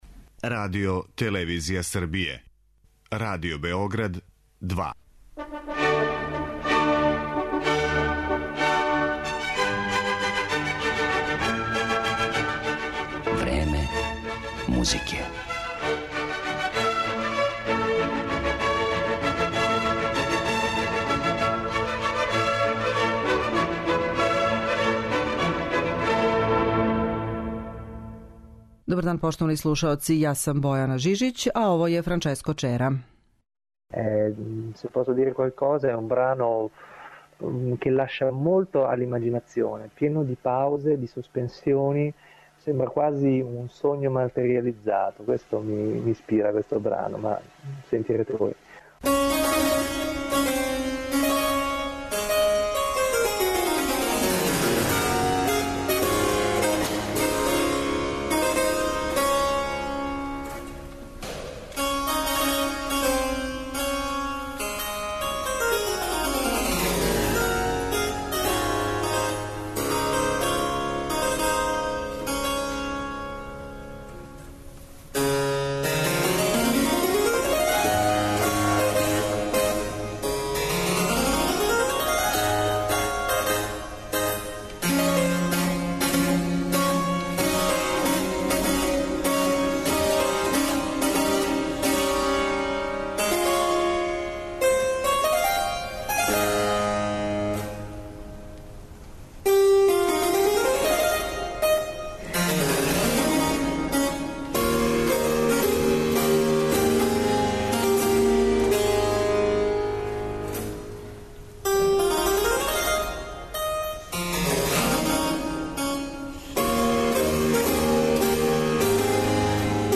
чембалисти и оргуљшу